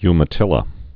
(ymə-tĭlə)